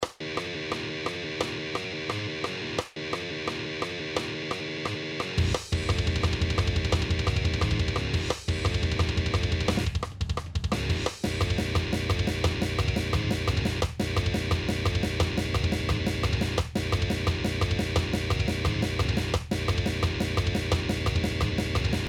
heavy metal
Very Groovy Riff and probably faster one in todays list.